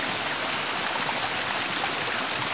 1 channel
wfall2.mp3